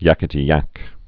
(yăkĭ-tē-yăk)